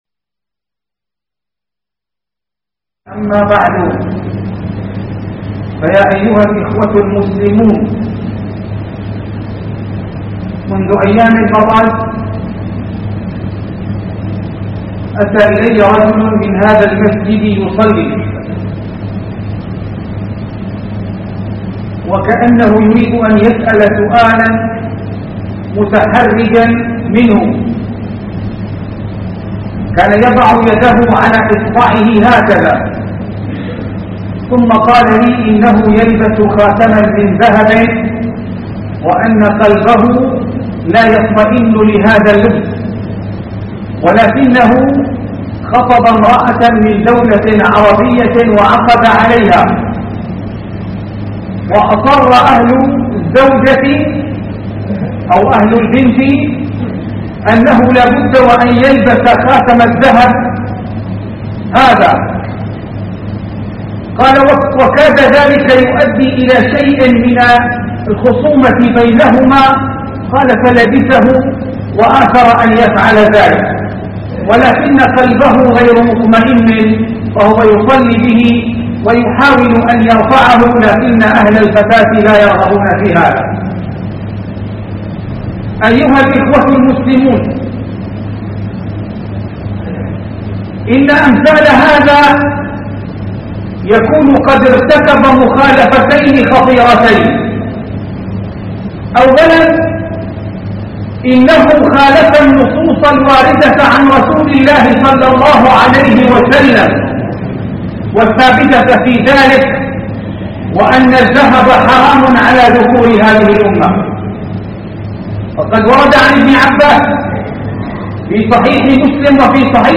محاضره بعنوان صلاة الجمعة حكم التختم بخاتم من الذهب